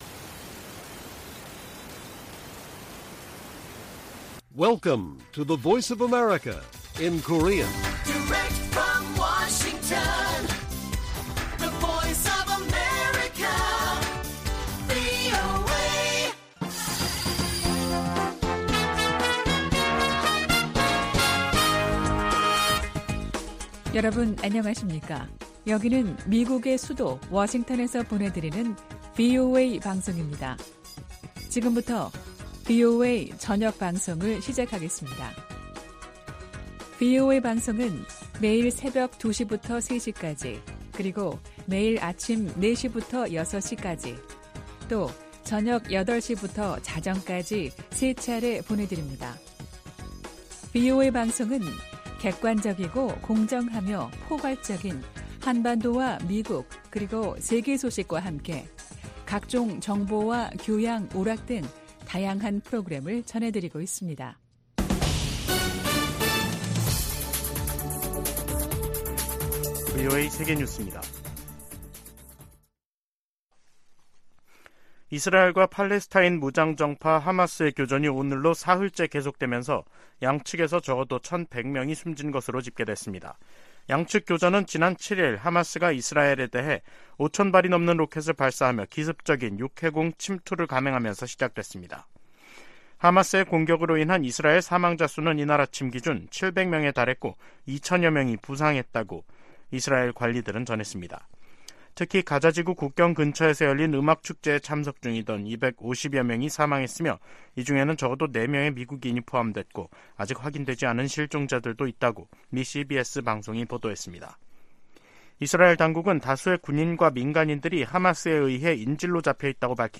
VOA 한국어 간판 뉴스 프로그램 '뉴스 투데이', 2023년 10월 9일 1부 방송입니다. 토니 블링컨 미 국무장관은 최근 한국에서 동결 해제된 이란 자금이 이스라엘 공격에 지원됐다는 일각의 주장을 일축했습니다. 한국에선 동북아 안보에 관한 미국의 집중도 하락, 북한이 중동의 전황을 반미연대 확대로 활용할 가능성 등이 제기되고 있습니다. 유럽연합(EU)은 러시아로 북한의 대포가 이전되기 시작했다는 보도에 관해 양국 무기 거래 중단을 촉구했습니다.